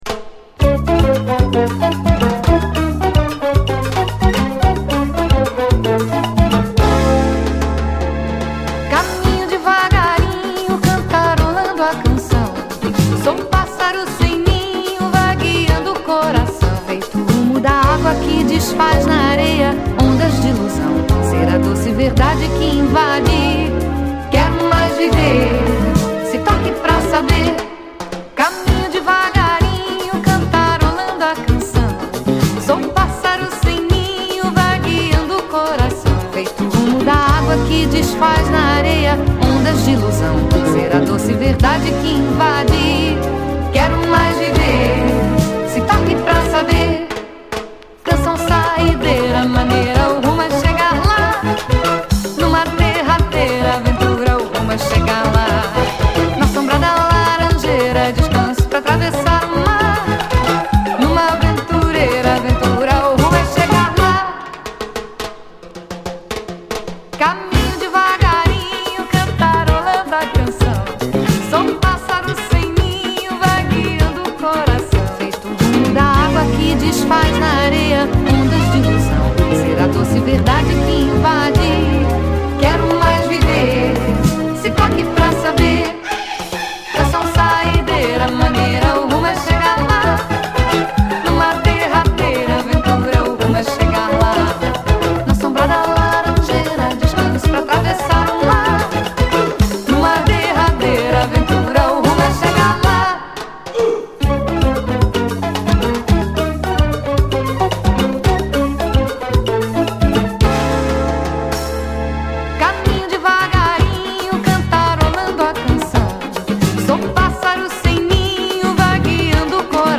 ブラジリアン・ソウル〜AOR好きを直撃するフロア・トラック！